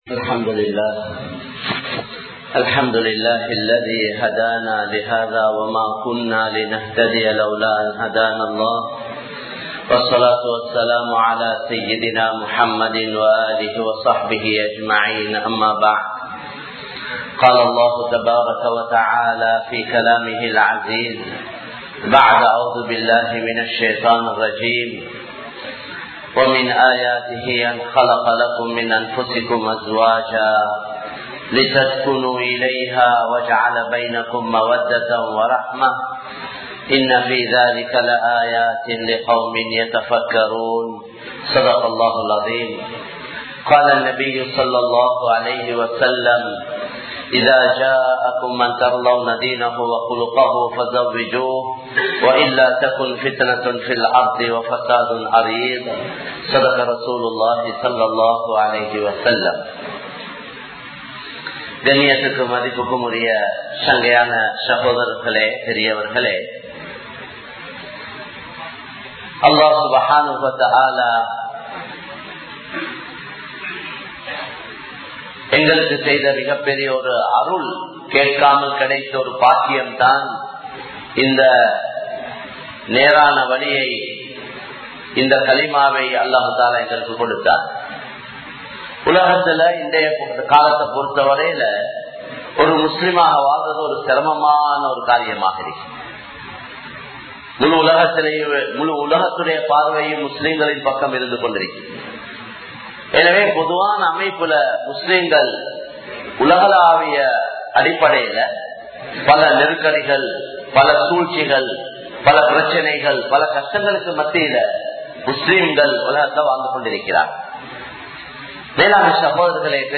Thirumanaththin Noakkam (திருமணத்தின் நோக்கம்) | Audio Bayans | All Ceylon Muslim Youth Community | Addalaichenai
Dehiwela, Muhideen (Markaz) Jumua Masjith